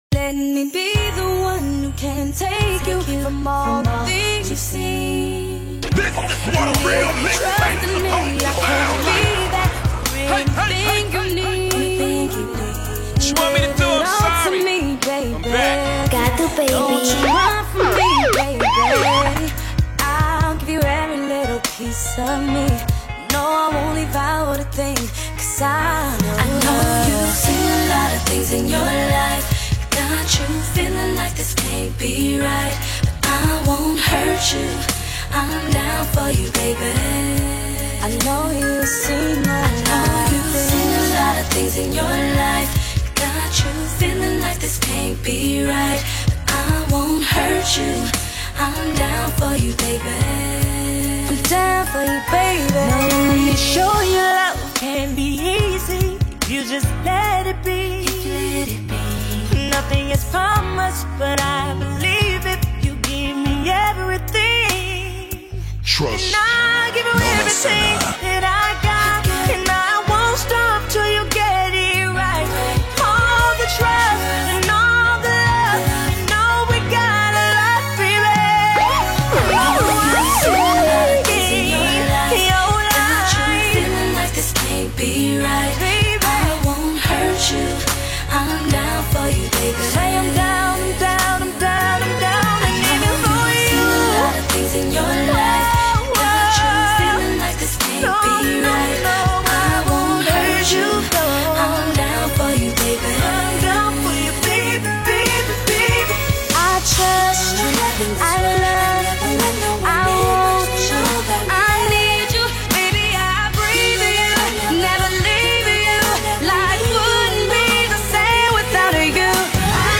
Mixtapes